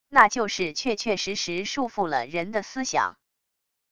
那就是确确实实束缚了人的思想wav音频